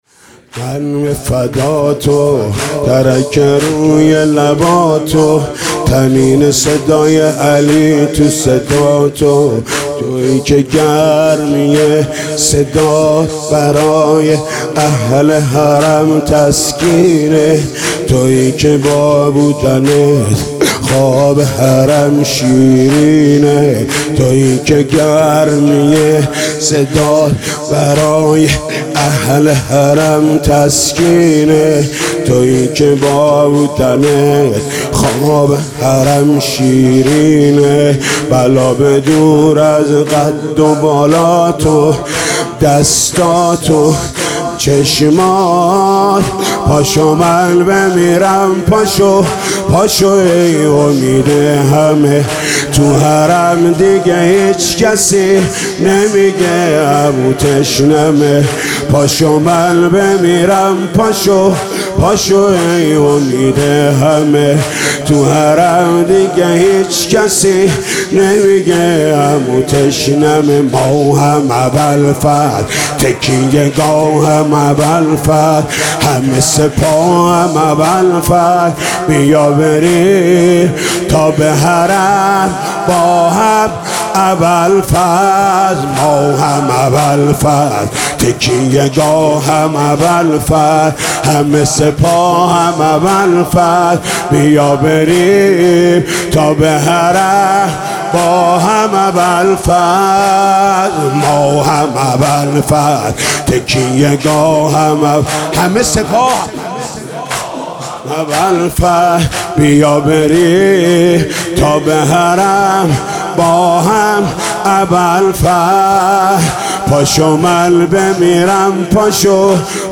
محرم96 - زمينه - مـن به فـدا تو تَرَك روى